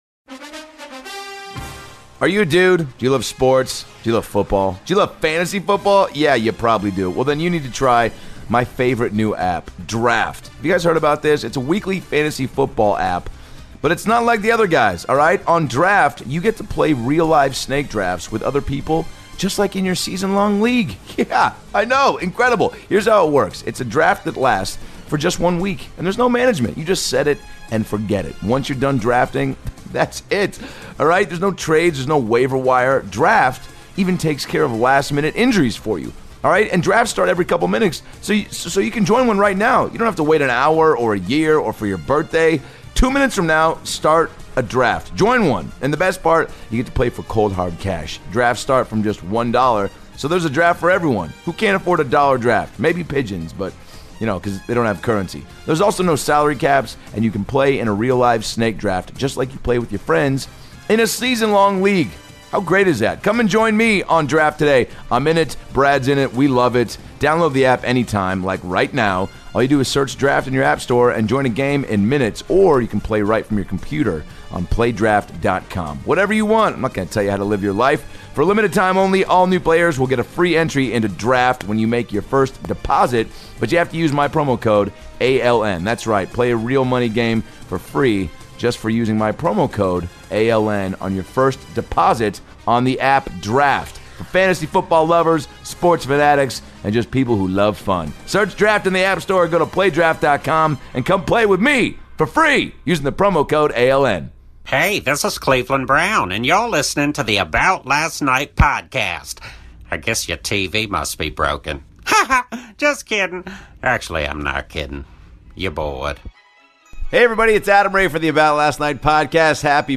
and do a TON of great impressions!